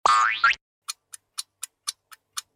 SFX_Random_Box_Get.mp3